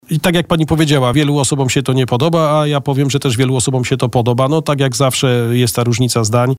Tauron, który musi likwidować awarię tego oświetlenia ulicznego, a pracuje całą dobę, realizuje też to w ciągu dnia. Wtedy, aby – powiem kolokwialnie – sprawdzić, które żarówki w oświetleniu nie świecą, musi to oświetlenie włączać, również w ciągu dnia, po to aby usuwać te awarie – objaśniał na naszej antenie Adam Ruśniak, zastępca prezydenta Bielska-Białej.